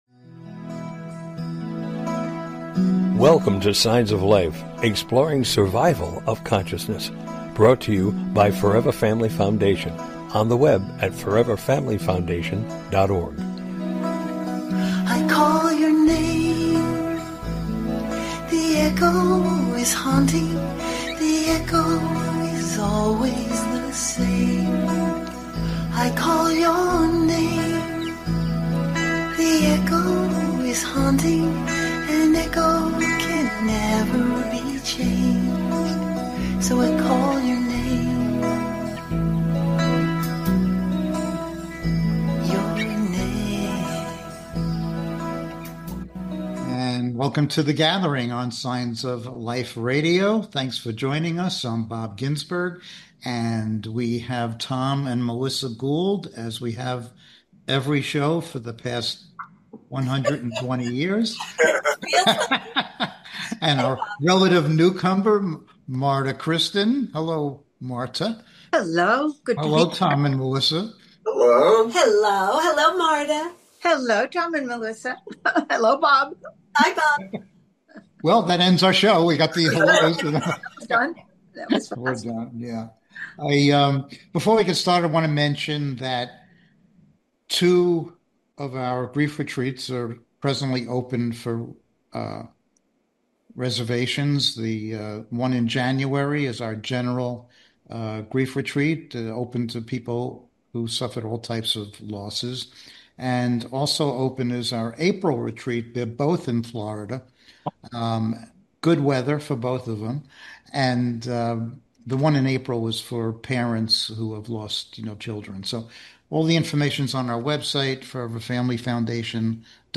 Join us this evening for an engaging live discussion on life after death and consciousness!
Tonight our panel will be addressing questions from our listeners that pertain to all matters related to life after death, consciousness, spirituality, etc.